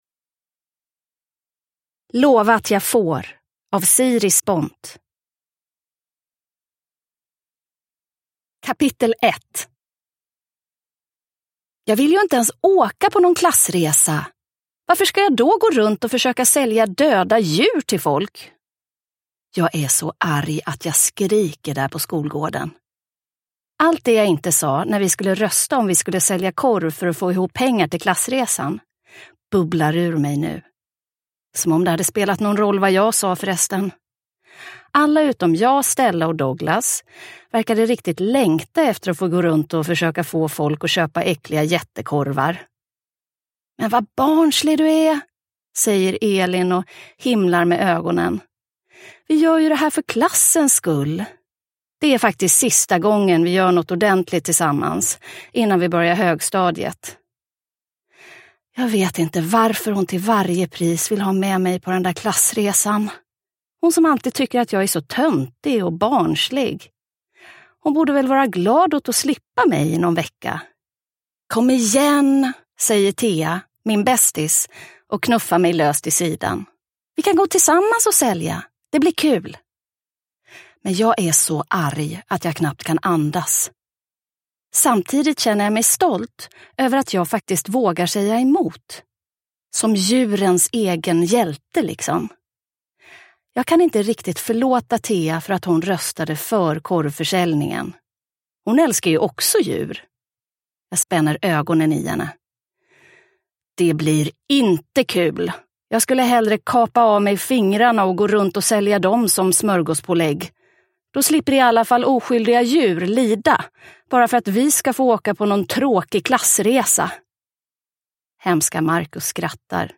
Lova att jag får – Ljudbok – Laddas ner